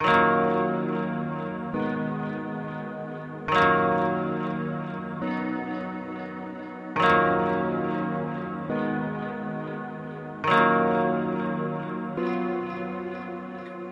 标签： 138 bpm Dance Loops Pad Loops 2.34 MB wav Key : Unknown
声道立体声